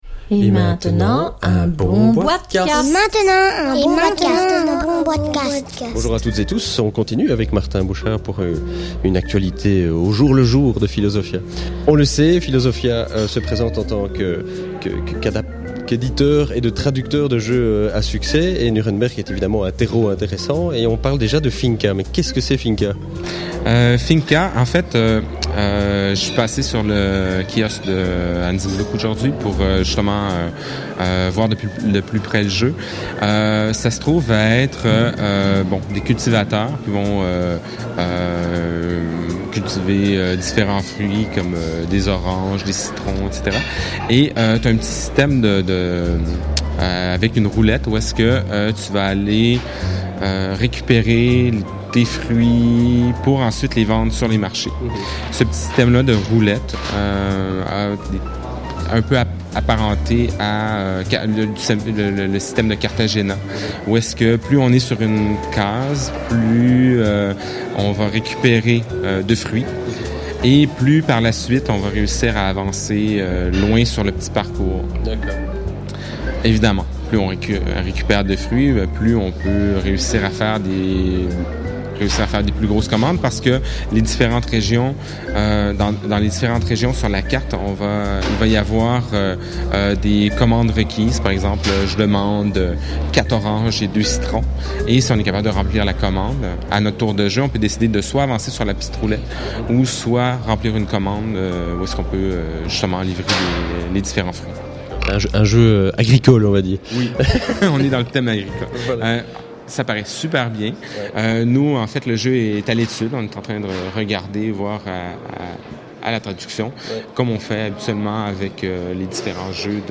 ( enregistré lors du Nuremberg SpielenwarenMesse 2009 )